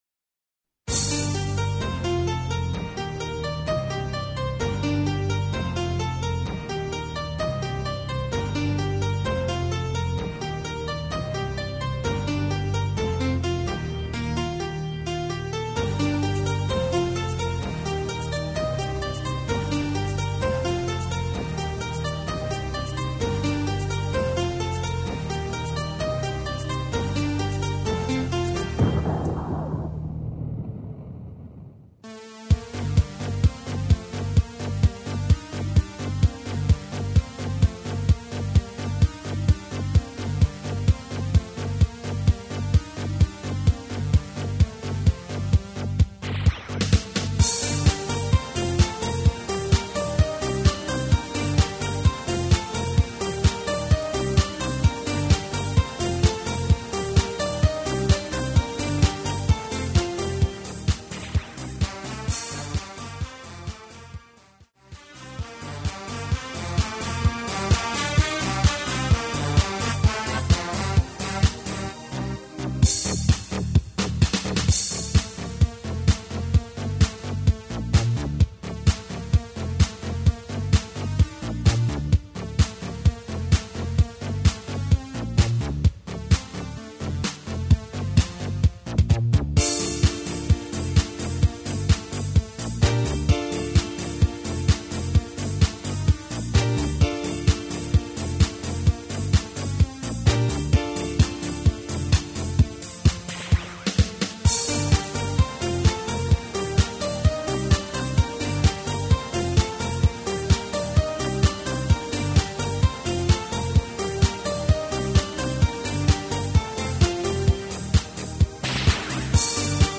HD = Hoedown/Patter